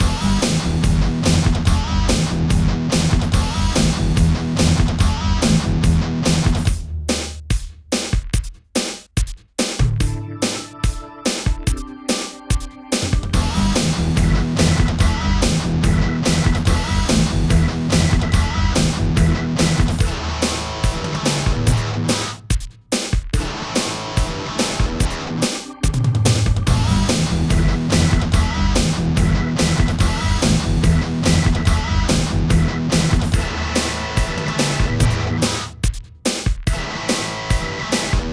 Rock heavy (bucle)
heavy
repetitivo
rock
sintetizador